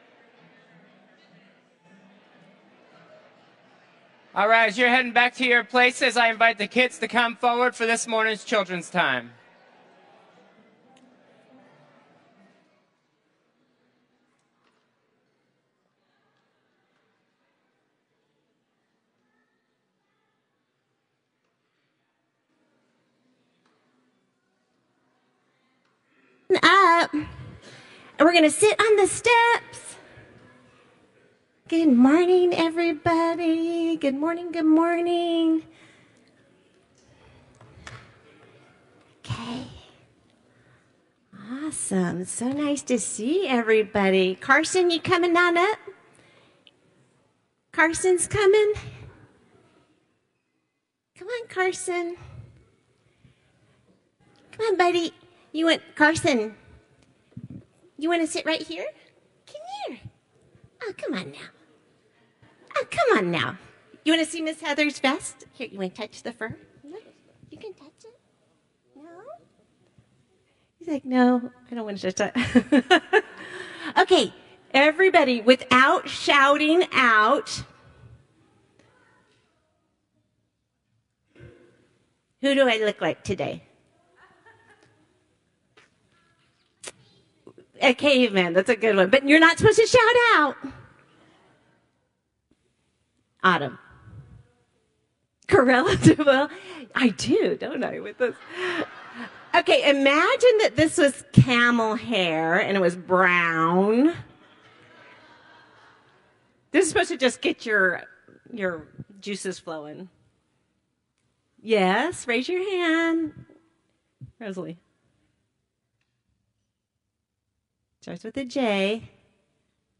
Children’s Time